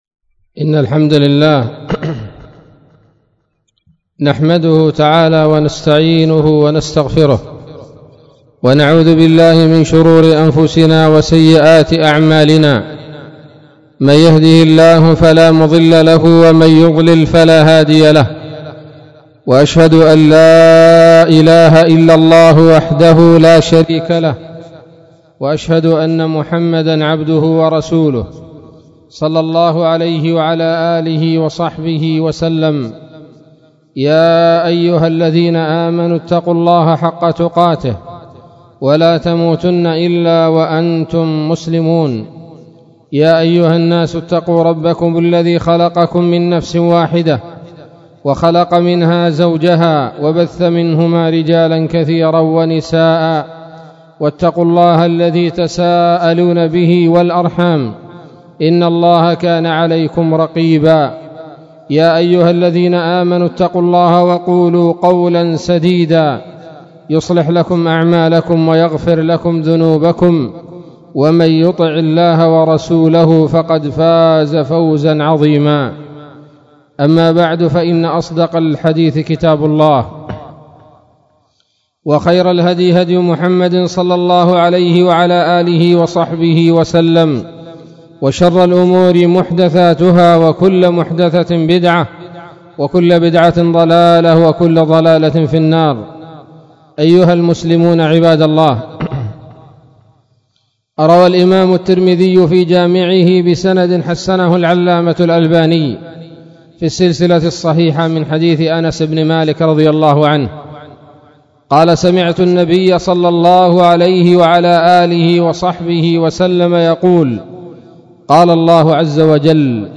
خطبة بعنوان : ((شرح الحديث القدسي عن أنس - رضي الله عنه - مرفوعا : قال الله تعالى : ( يابن آدم إنك ما دعوتني ورجوتني...